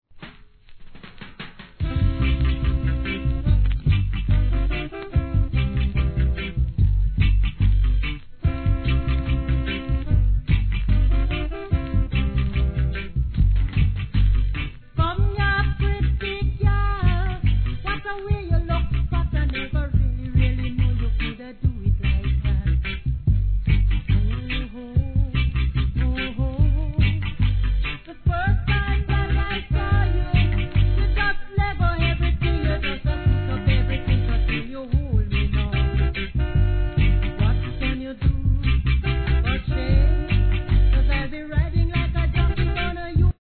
盤にスレのため前半にややサーッと音入ります（試聴確認ください）
REGGAE